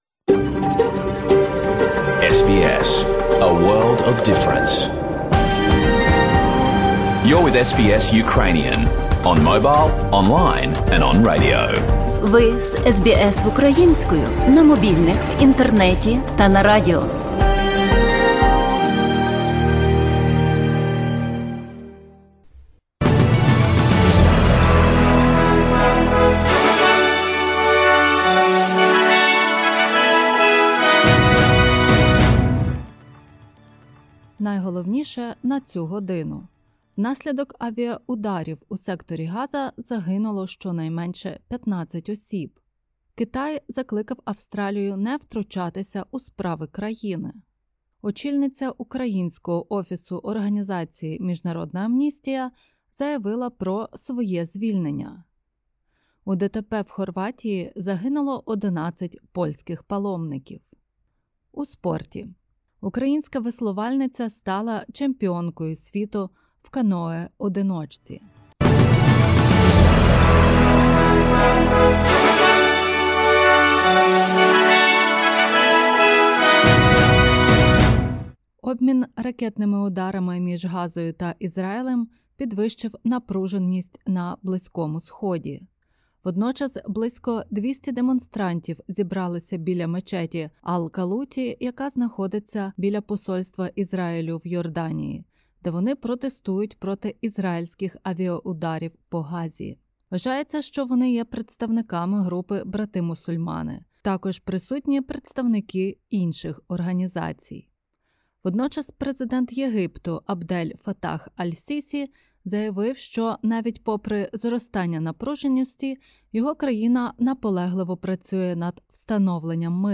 Latest news from Australia and the world.